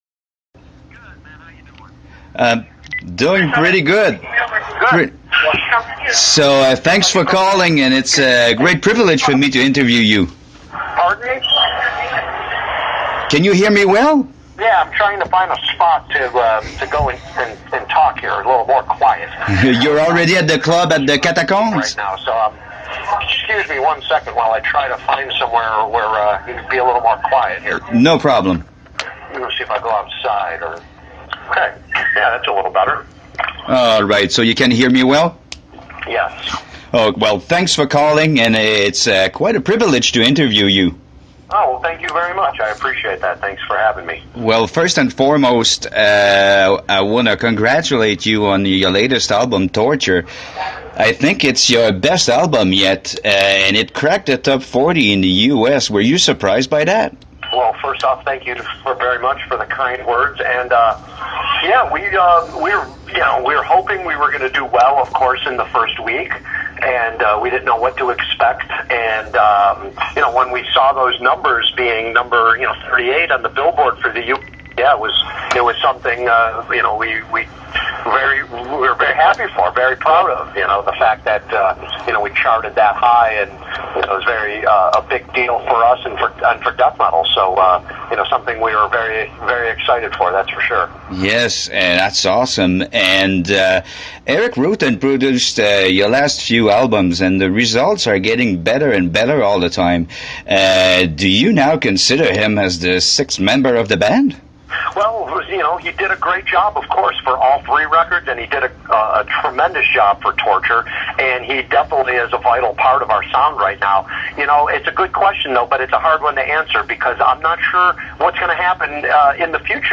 Entrevue avec Paul Mazurkiewicz de Cannibal Corpse